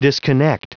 Prononciation du mot disconnect en anglais (fichier audio)
Prononciation du mot : disconnect